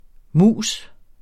MUS eller mus forkortelse Udtale [ ˈmuˀs ]